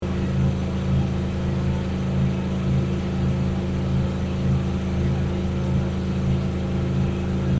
machine.mp3